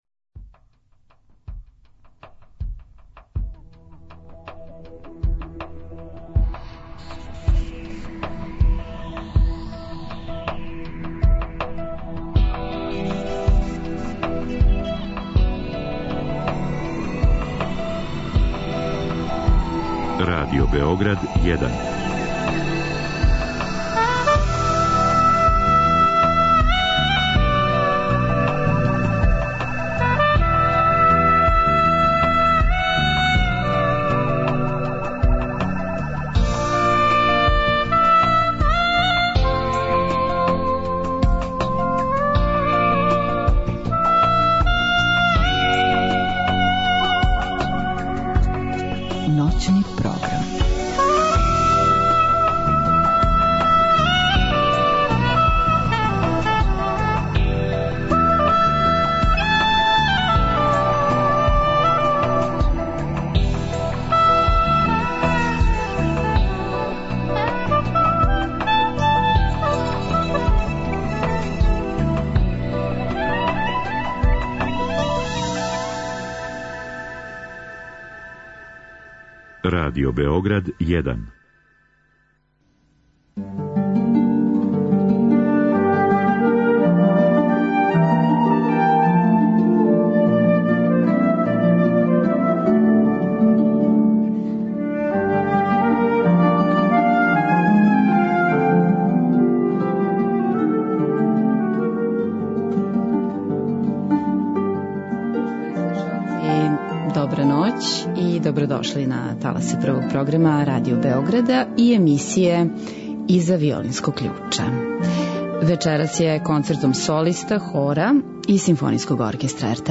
Ноћни програм реализујемо из Неготина где се вечерас завршавају 50. 'Мокрањчеви дани'.